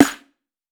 TC SNARE 09.wav